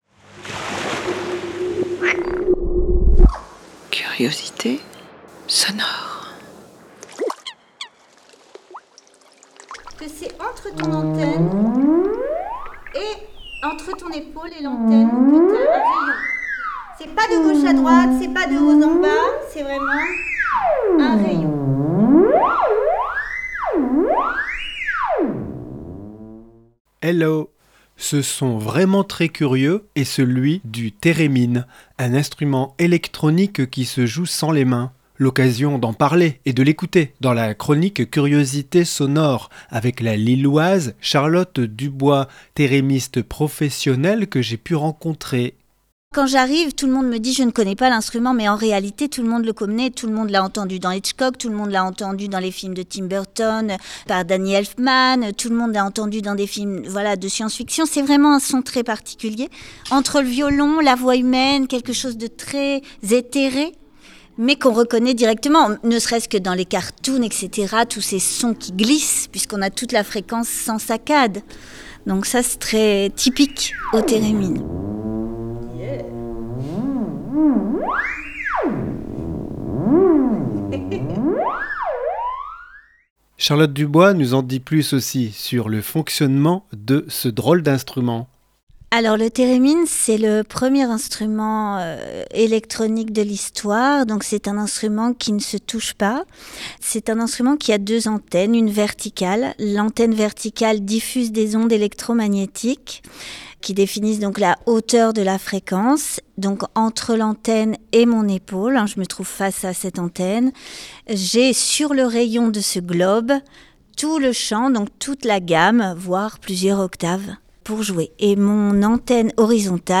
Le Theremin est un instrument électronique vraiment très curieux qui se joue sans les mains.